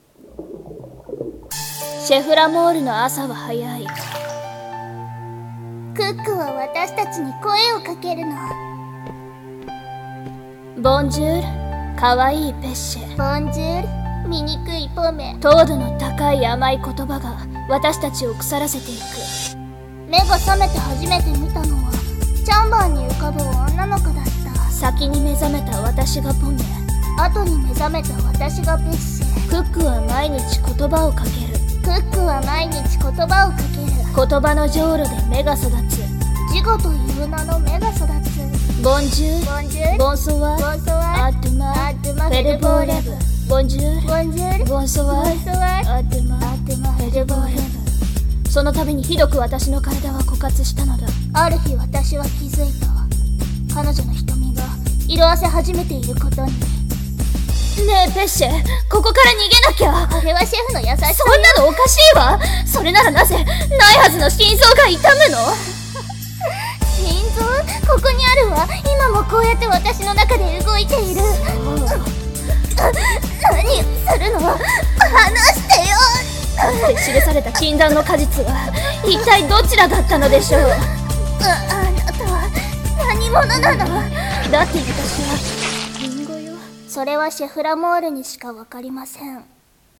CM風声劇「ポミェとペッシェ」